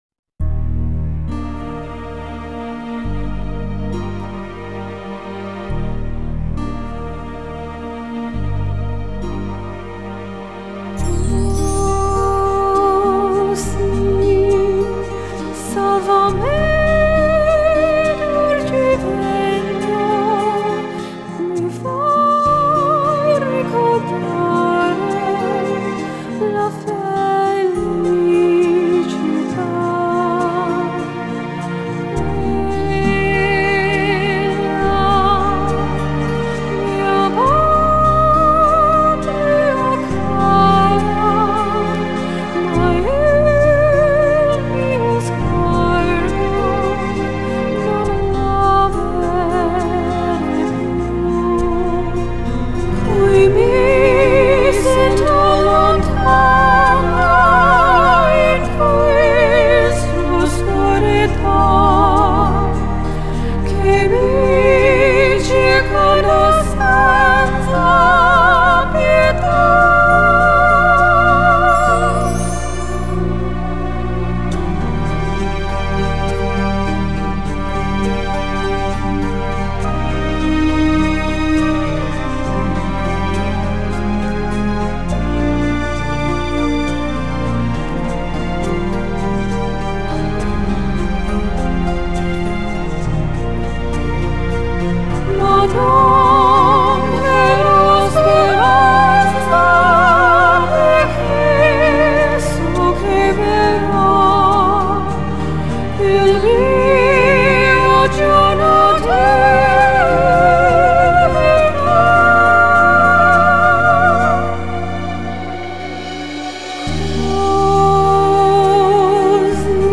Genre: Electronic, Classical Crossover, Singer-SAongwriter